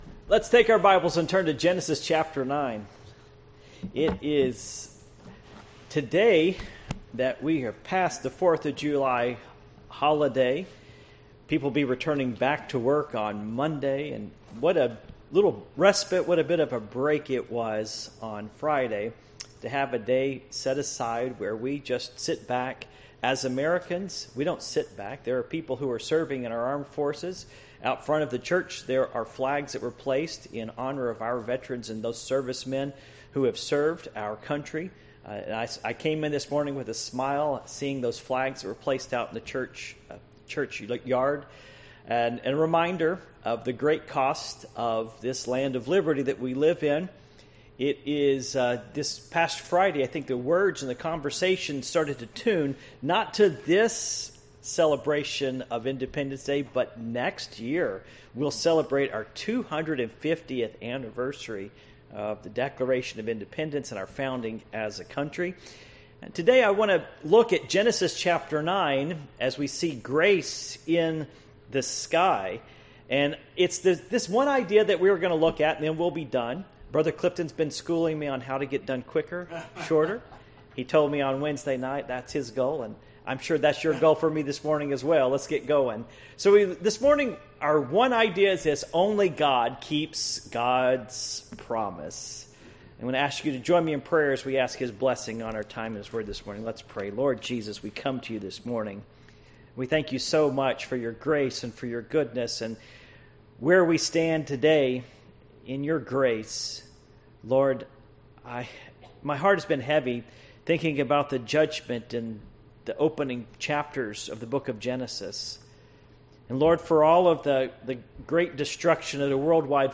Passage: Genesis 9:1-17 Service Type: Morning Worship